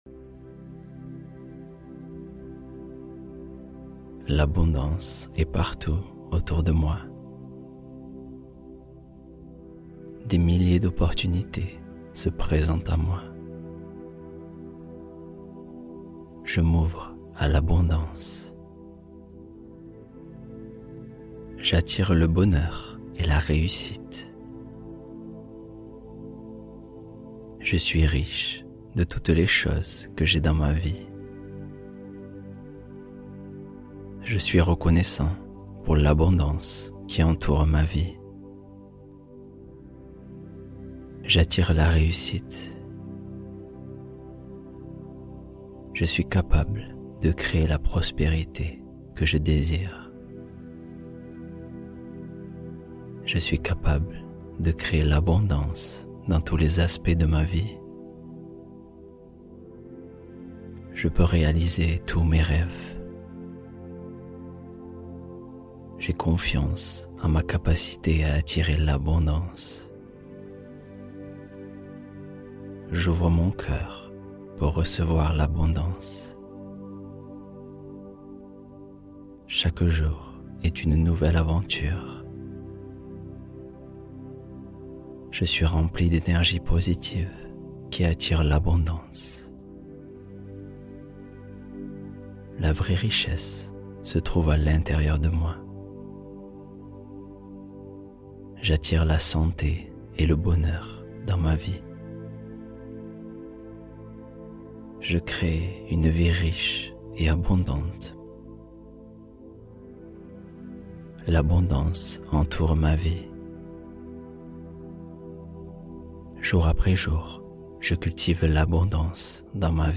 Attirez L'ABONDANCE Pendant Votre Sommeil | Affirmations Qui Reprogramment Votre Réussite